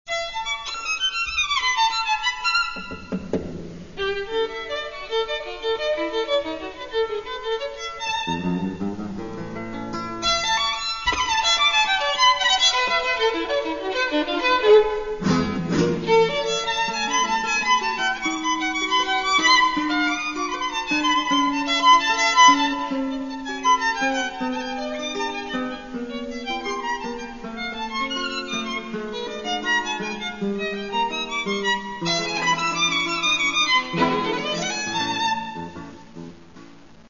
Тому що це – запис живого концерту.